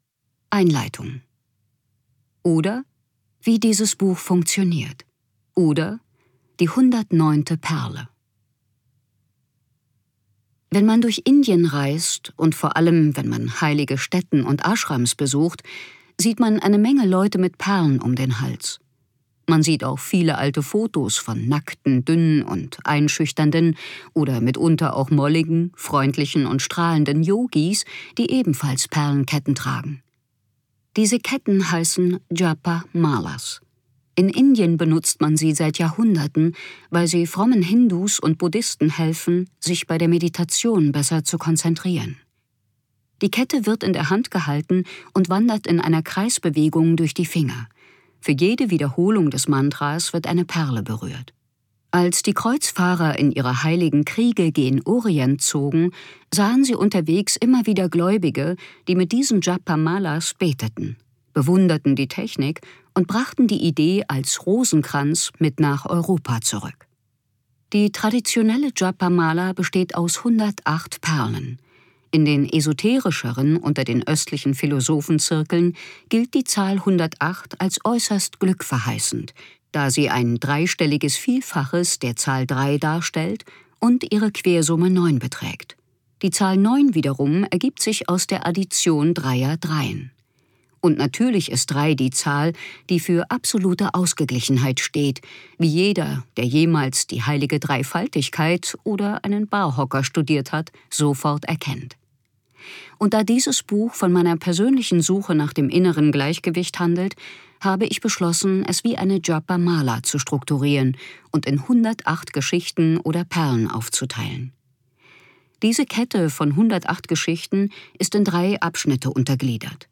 Eat, Pray, Love - Elizabeth Gilbert | argon hörbuch
Gekürzt Autorisierte, d.h. von Autor:innen und / oder Verlagen freigegebene, bearbeitete Fassung.